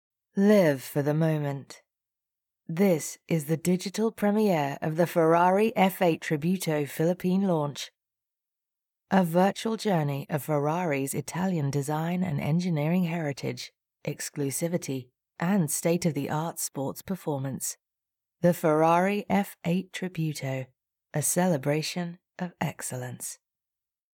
Female
Radio / TV Imaging
British I Ferrari
Words that describe my voice are Conversational, Believable, Engaging.
All our voice actors have professional broadcast quality recording studios.